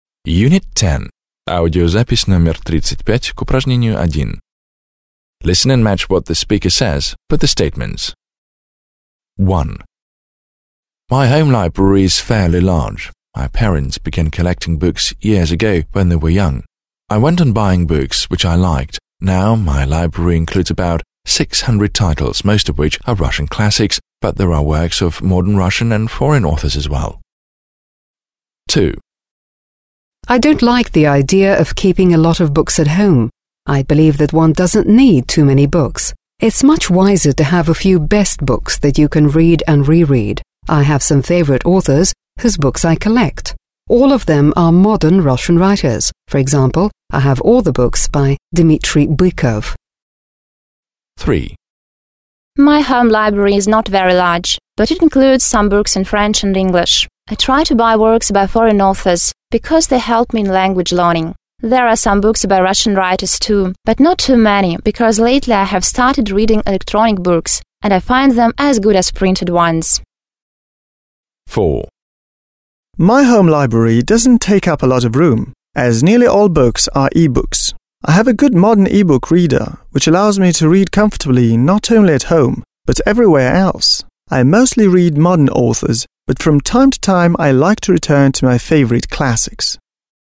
1. Послушайте, как четыре человека рассказывают о своих домашних библиотеках, (35), и сопоставьте их слова со следующими утверждениями.